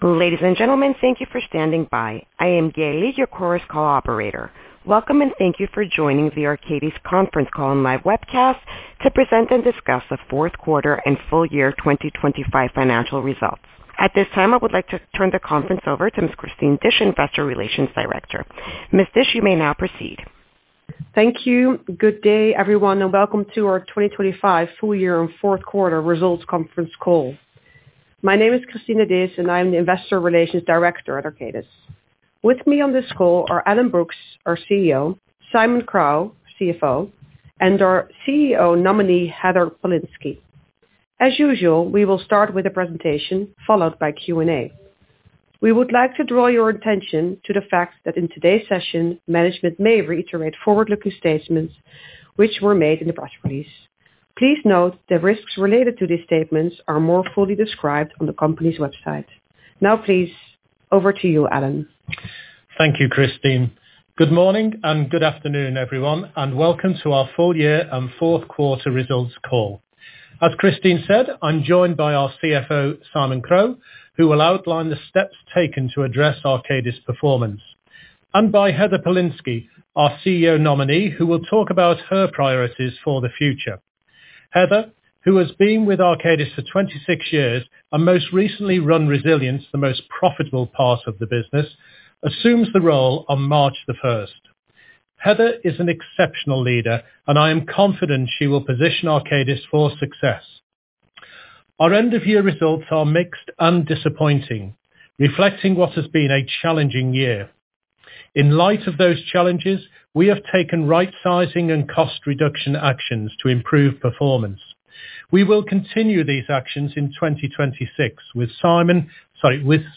2025-q4-arcadis-results-webcast-audio-recording.mp3